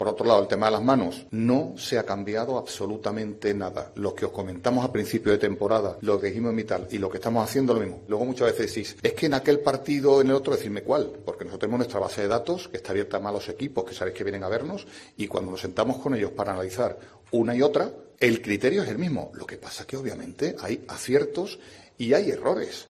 "No se ha cambiado absolutamente nada el criterio sobre las manos respecto al principio de temporada. Luego, cada semana hay jugadas debatibles, es normal; pero el criterio es el mismo aunque luego hay aciertos y errores", dijo en una rueda de prensa en Riad, capital de Arabia Saudí, donde se disputará la Supercopa de España.